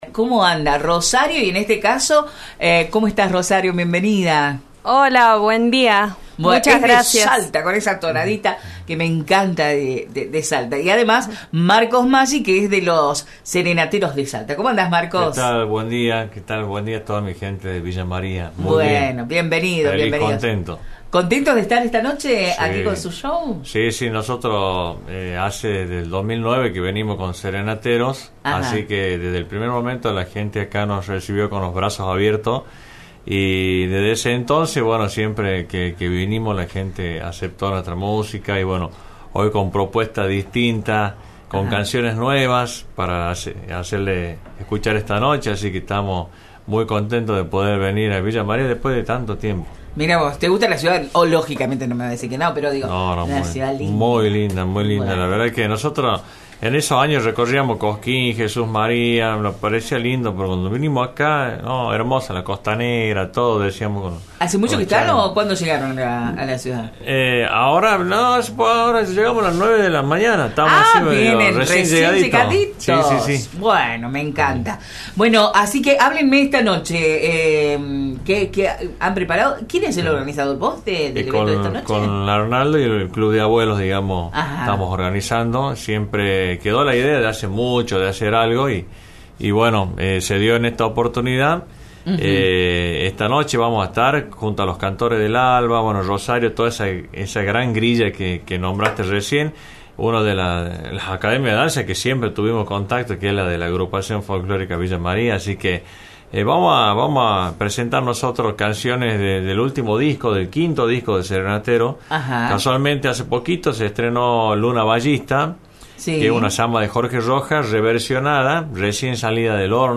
A partir de las 21.00, este viernes en el Club de Abuelos de Villa María, se hará una Gran Peña Folclórica para toda la familia y las entradas anticipadas en venta están en Verdulería «El Negro», sobre Bv. Vélez Sarsfield, a metros de la rotonda de España y el mencionado bulevar. Estuvimos charlando con dos de los artistas que están en la grilla